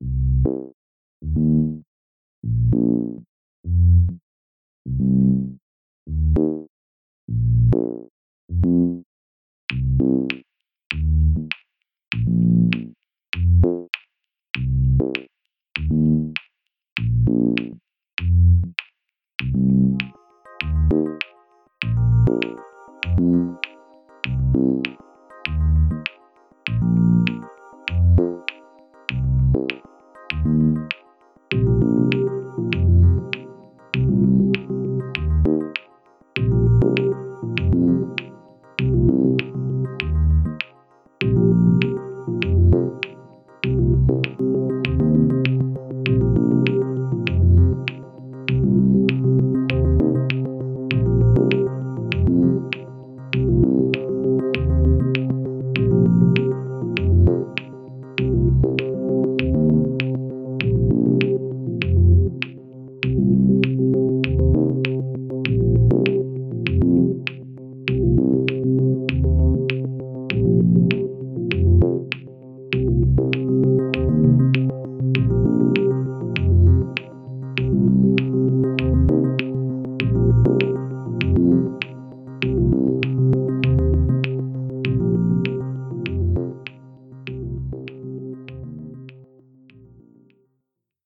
De atunci, bulgarele rostogolit continua sa se rostogoleasca… si, chiar daca azi nu mai proiectez DSP sau circuite integrate, fac uneori muzica electronica.